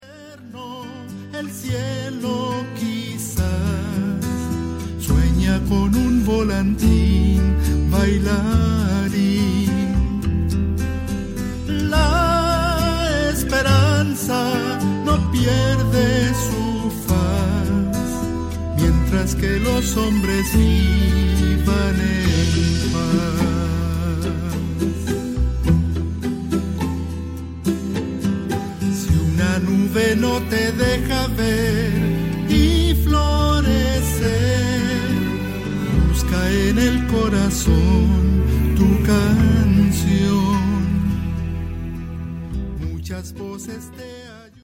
Ein ganz persönliches Latin Album